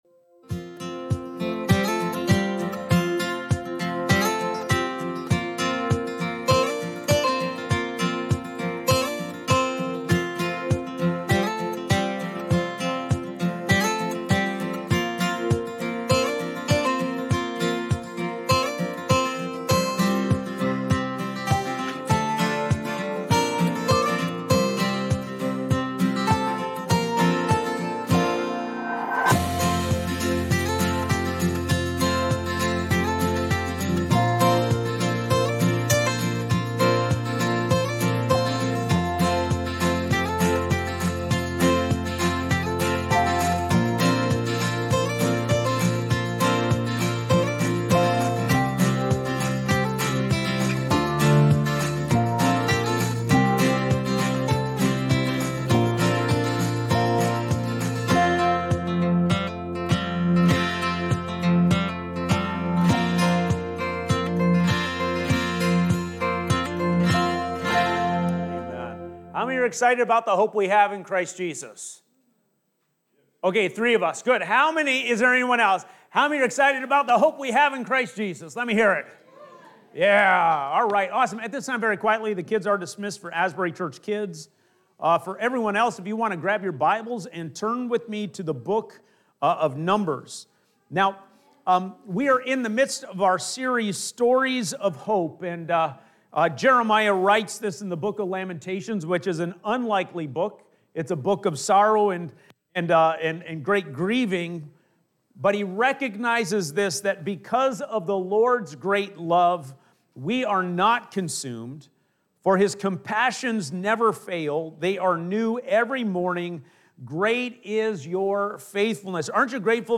Getting-Through-Sermon.mp3